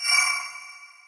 mainmenu_mouseover.wav